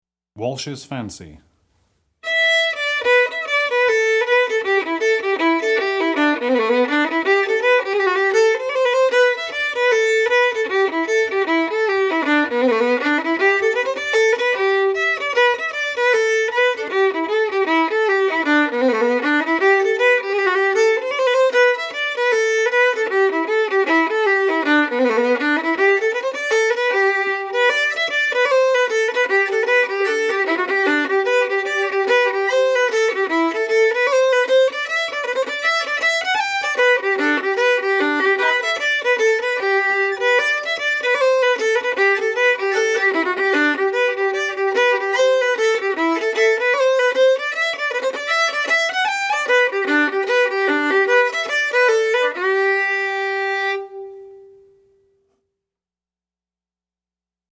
FIDDLE SOLO Fiddle Solo, Celtic/Irish, Reel
DIGITAL SHEET MUSIC - FIDDLE SOLO